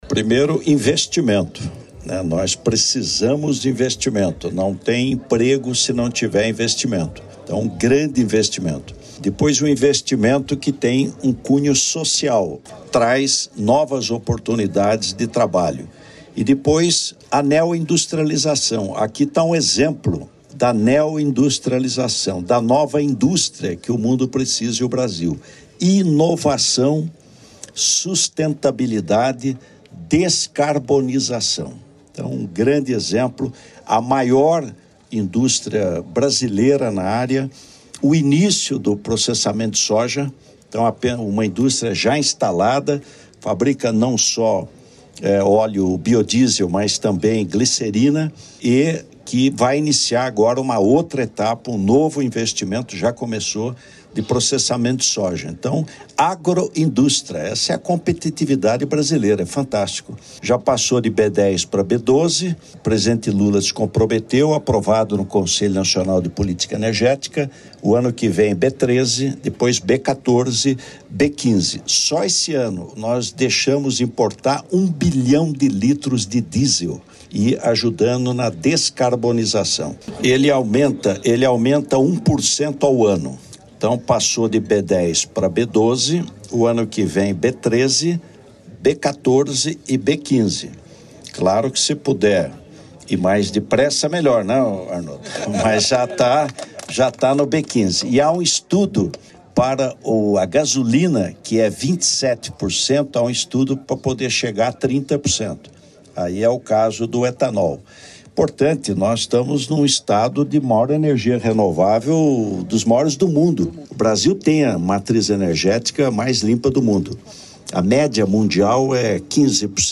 Sonora do vice-presidente da República, Geraldo Alckmin, sobre o investimento de R$ 1,7 bilhão do Grupo Potencial para ampliar a produção de biodiesel no Paraná | Governo do Estado do Paraná